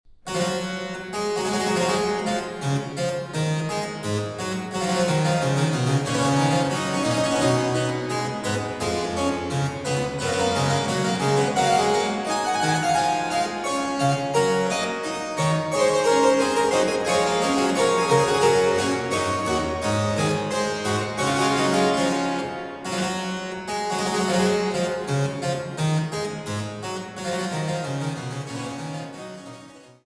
Meisterwerke der französischen Gambenmusik